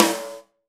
SNARE 004.wav